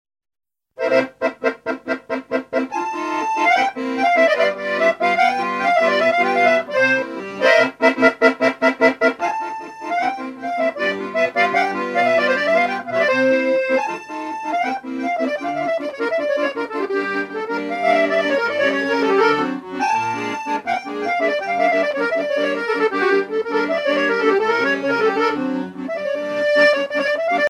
danse : kolo (Serbie)
Pièce musicale éditée